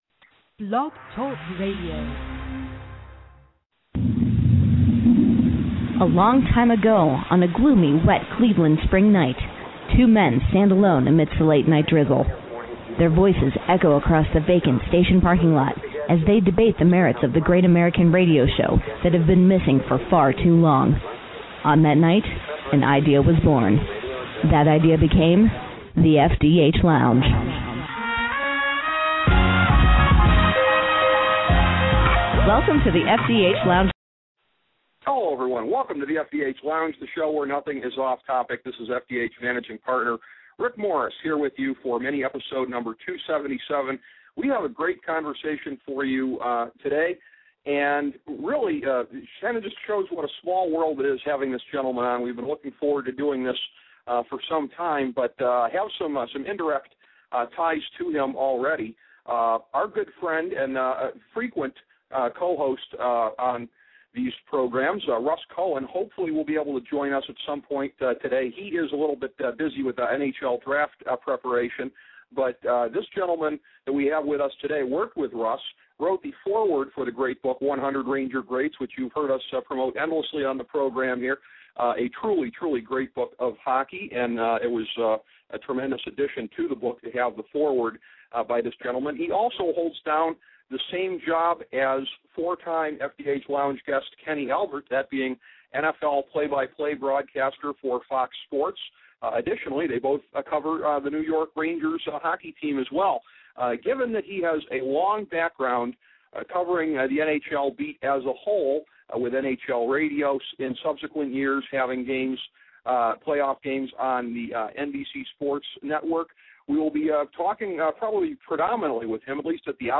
A conversation with Sam Rosen